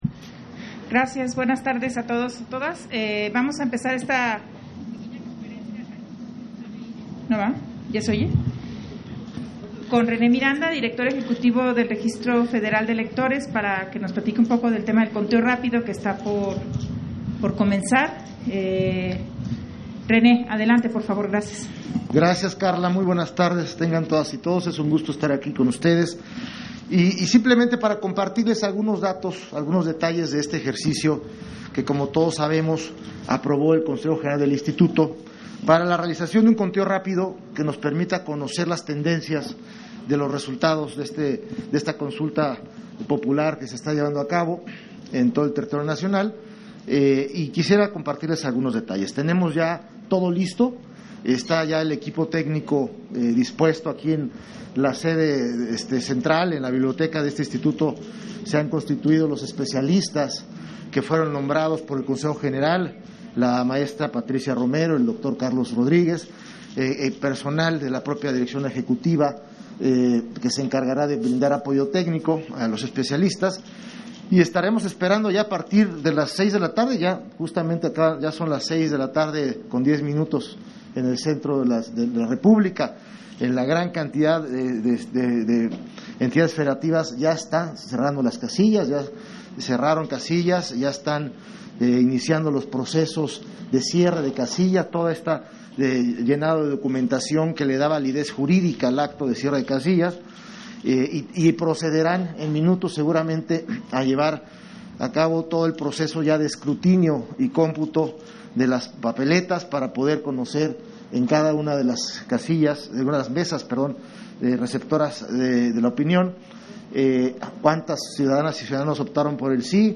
010821_AUDIO_CONFERENCIA-DE-PRENSA-DIRECTOR-EJECUTIVO-DEL-REGISTRO-FEDERAL-DE-ELECTORES